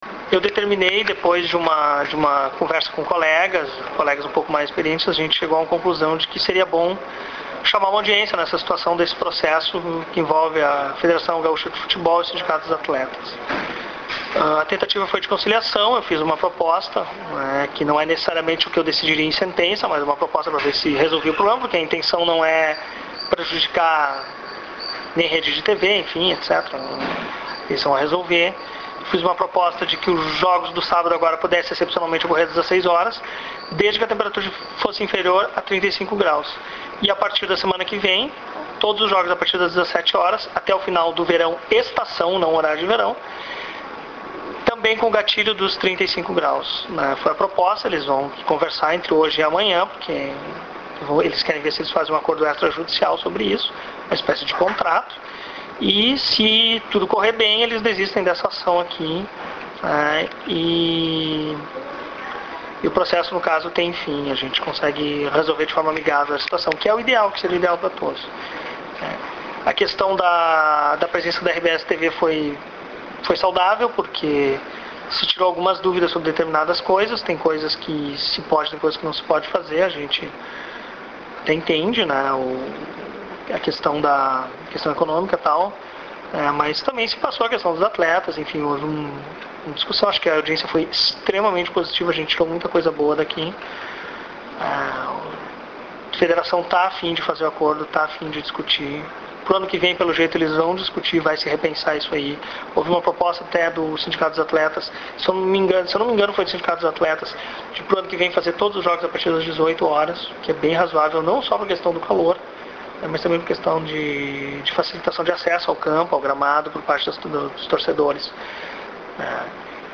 Clique no ícone de áudio ao lado do título para ouvir ou fazer o download da manifestação do Juiz Rafael da Silva Marques, sobre a audiência de conciliação realizada hoje (09/2)